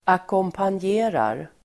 Uttal: [akåmpanj'e:rar]
ackompanjerar.mp3